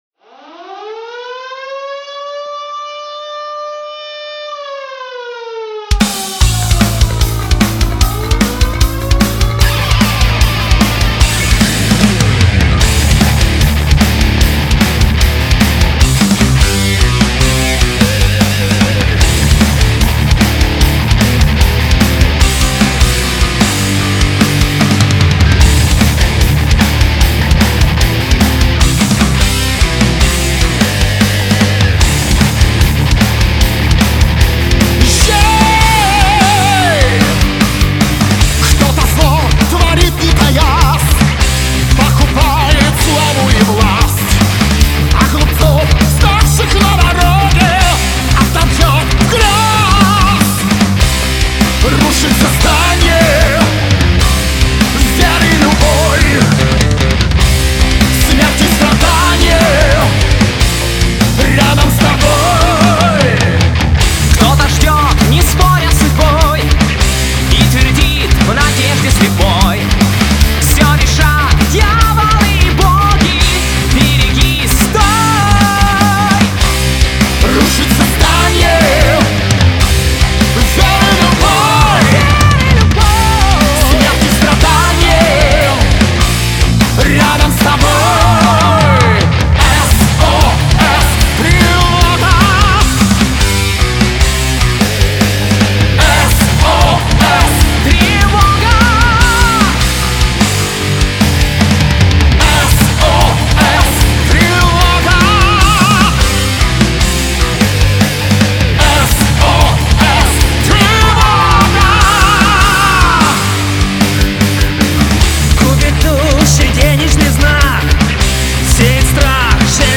вокал
гитары
бас, бэк-вокал